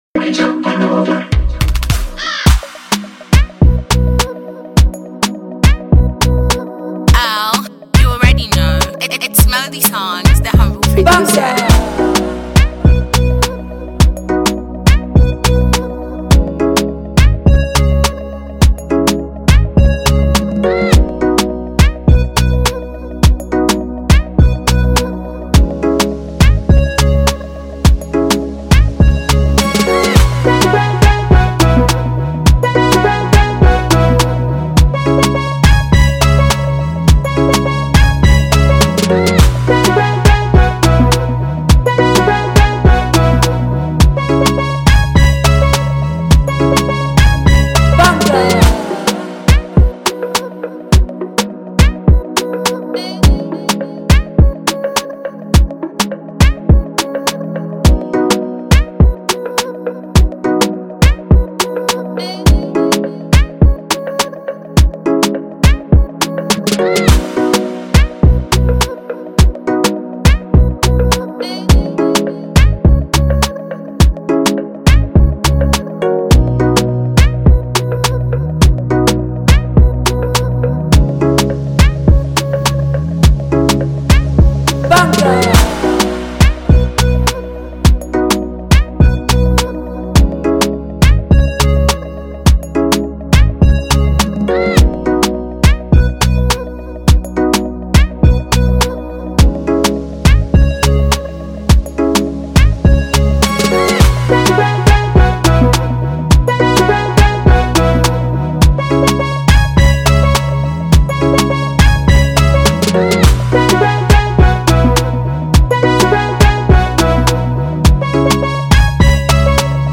Download remake beat Instrumental